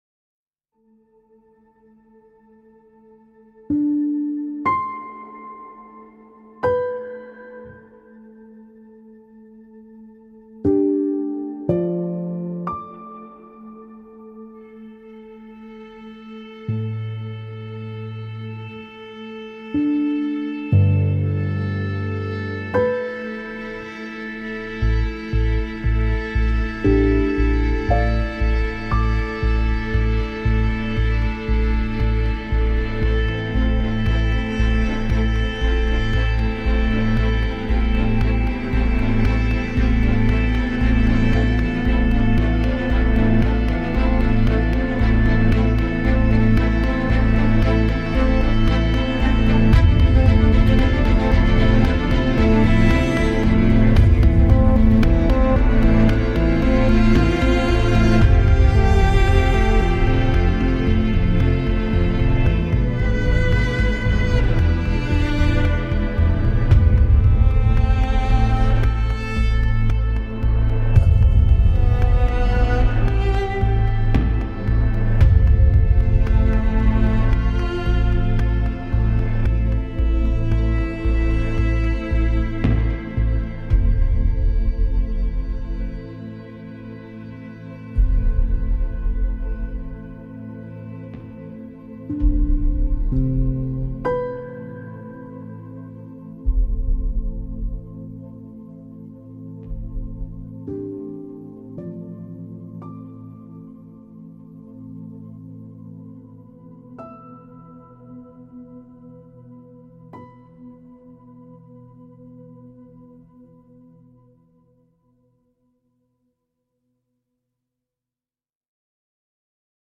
piano et quintette à cordes